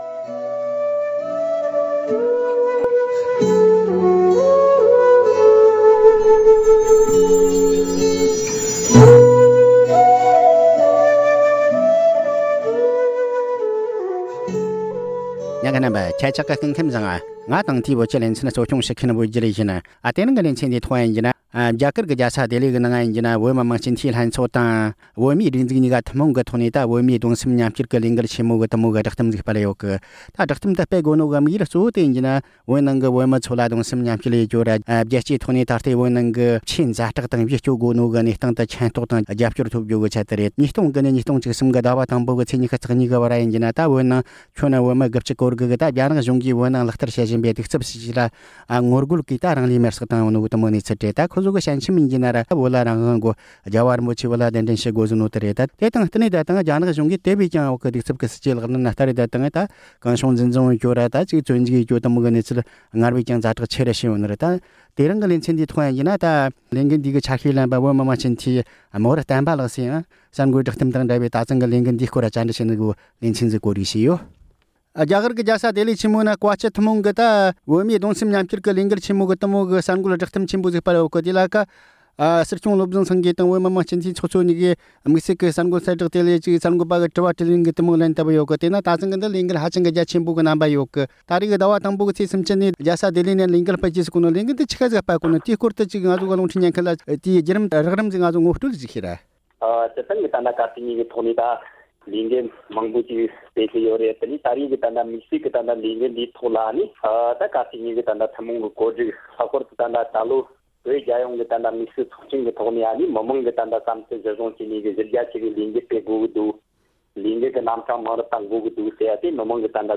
བོད་མིའི་གདུང་སེམས་མཉམ་བསྐྱེད་ལས་འགུལ་ཆེན་མོའི་གསར་འགོད་བསྒྲགས་གཏམ་དང་འགུལ་སྤེལ་ཕྱོགས་ཐད་བཅར་འདྲི་ཞུས་པ།